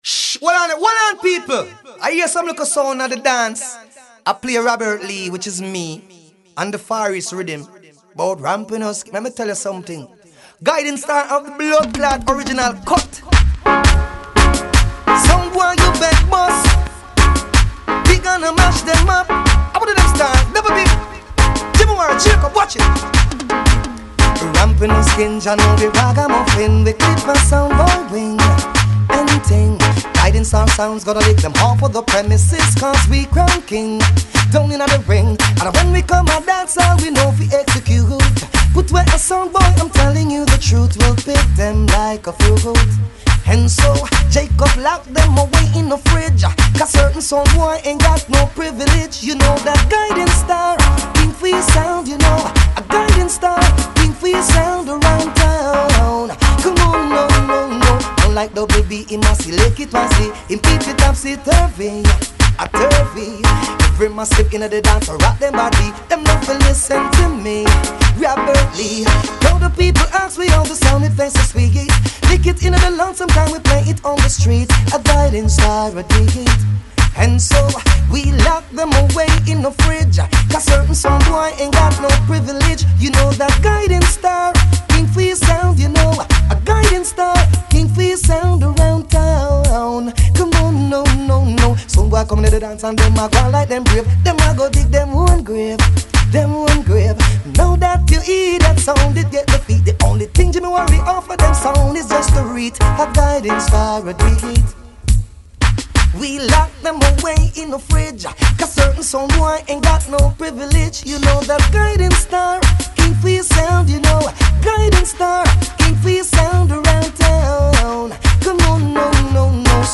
Raggae sound System music